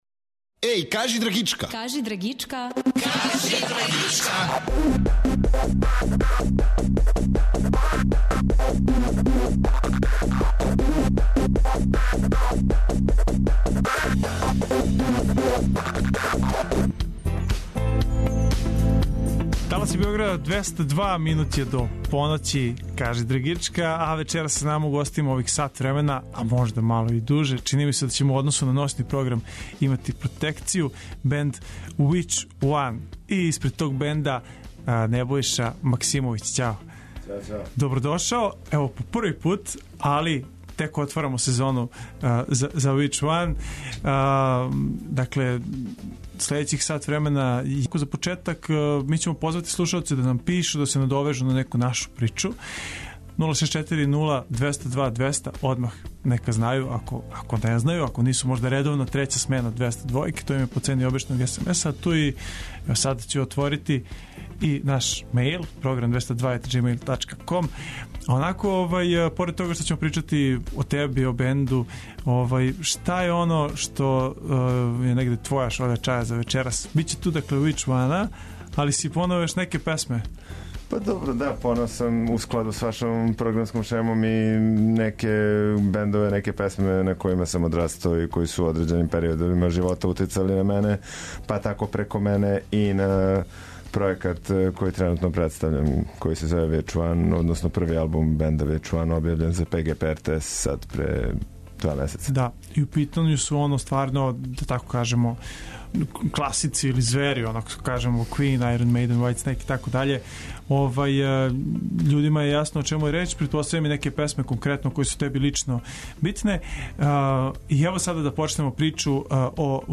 Ноћас са нама бенд за који сте питали много пута - београдска рок група WITCH 1.